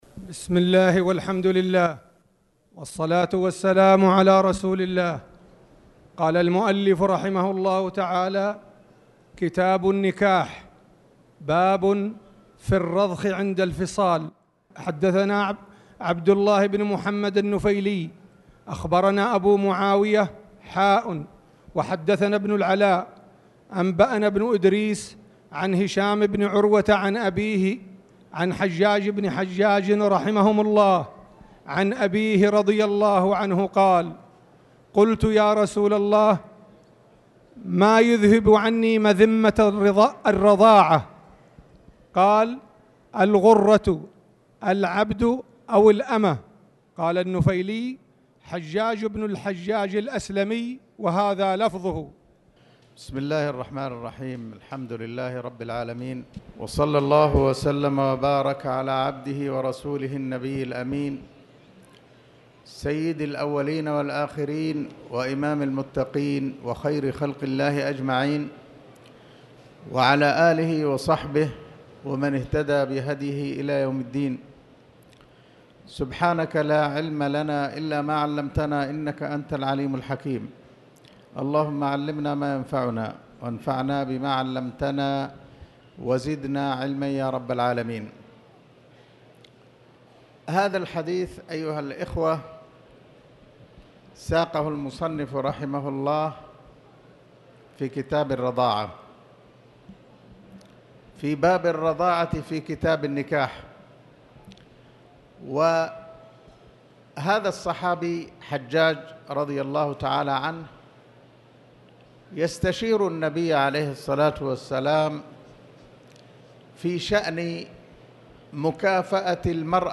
تاريخ النشر ٦ رمضان ١٤٣٧ هـ المكان: المسجد الحرام الشيخ